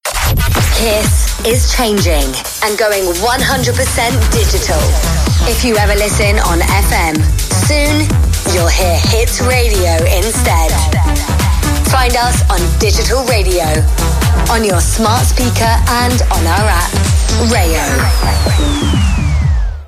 Promo-Kiss-is-Changing-2024.mp3